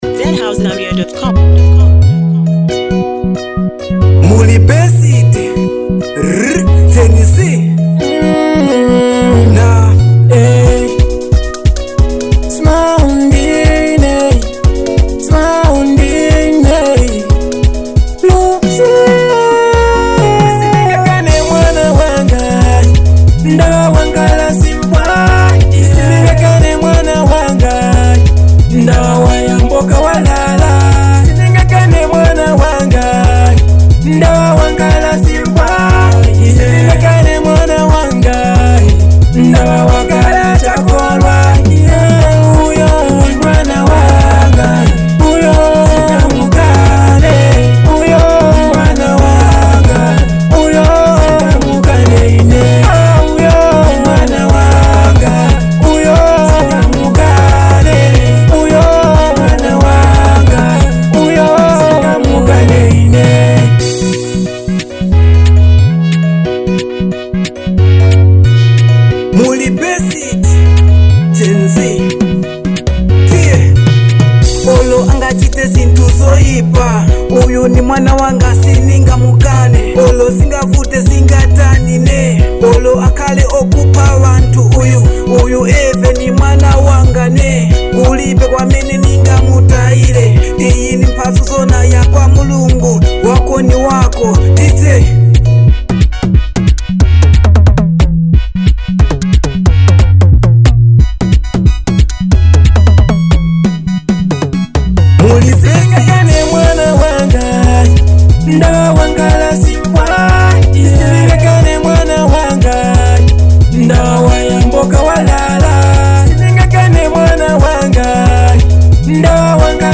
soulful vibe
smooth vocals and powerful lyrics
With its catchy melody and deep message